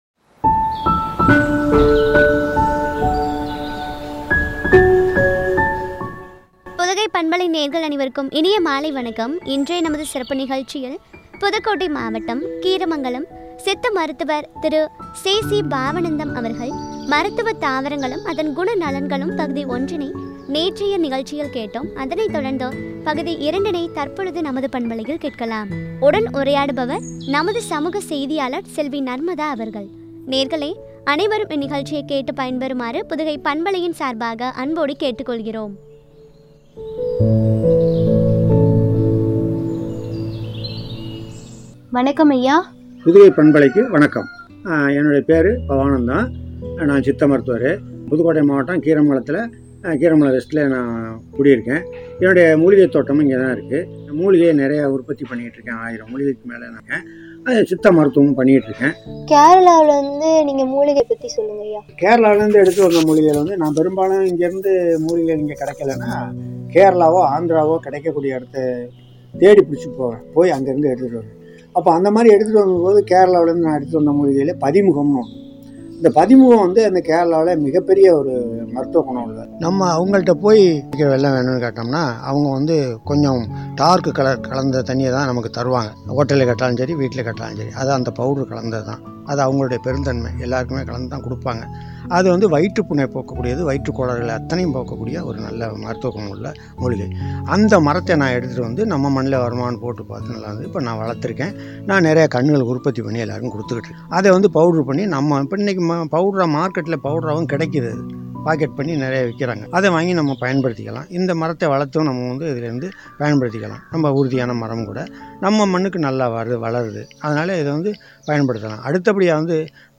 என்ற தலைப்பில் வழங்கும் உரையாடல்.